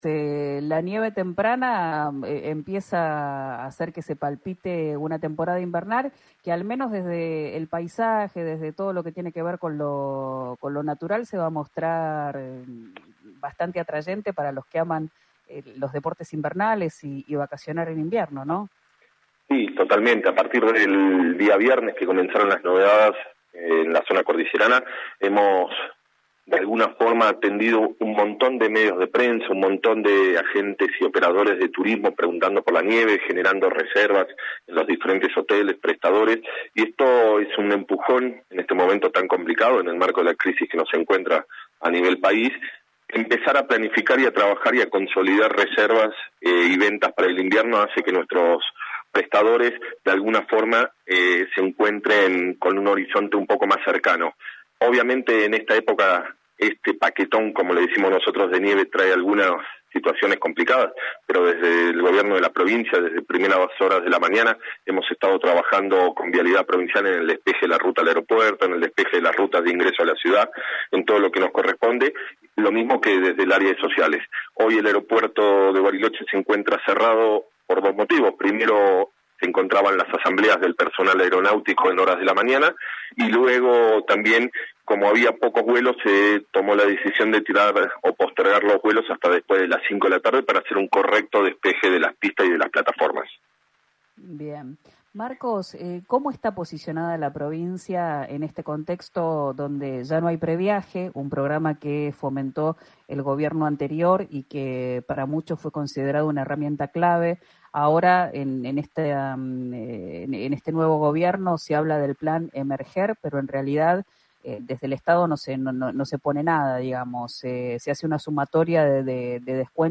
Escuchá a Marcos Barberis, secretario de Turismo de Río Negro